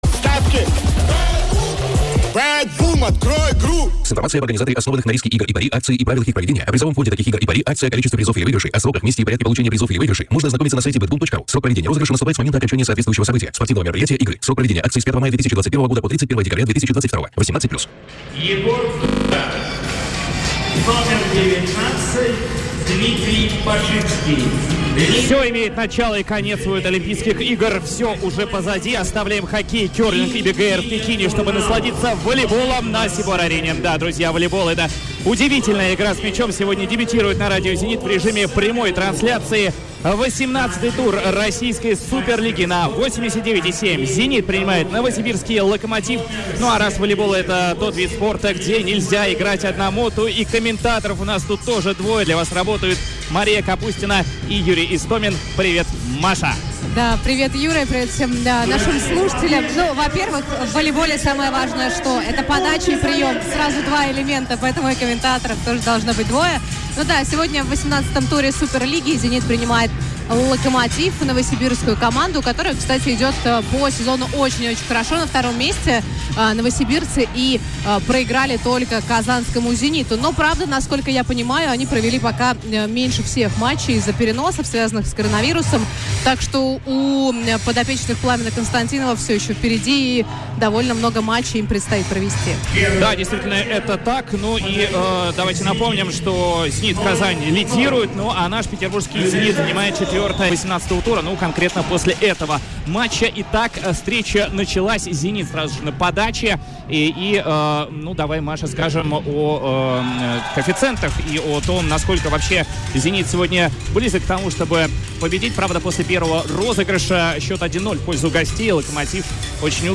Аудиозапись трансляции на «Радио Зенит»